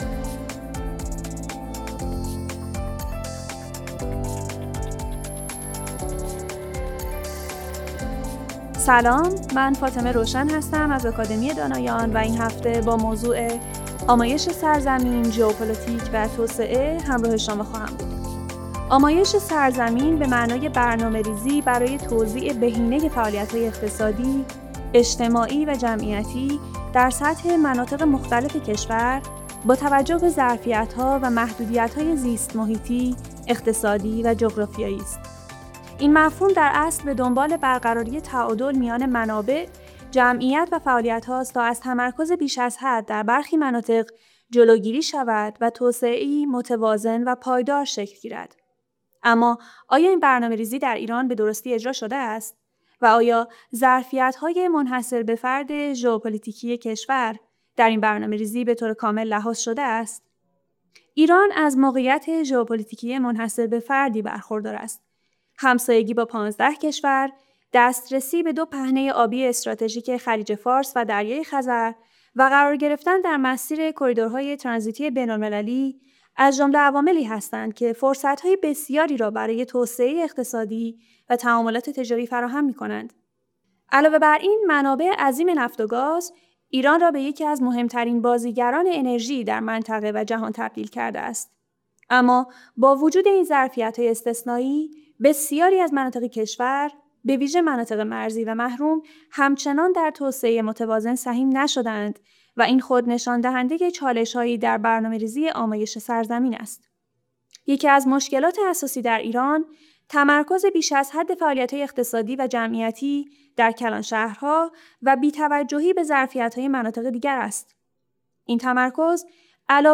فایل صوتی مناظره